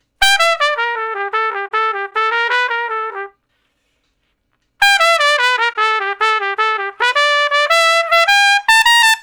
084 Trump Shuffle (E) 05.wav